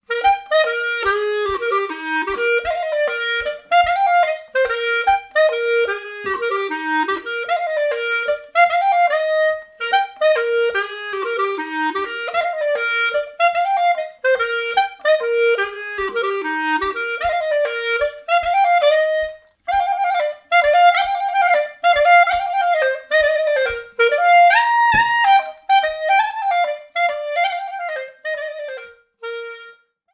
Total speltid: 34.05 Spelet är fint och lyhört.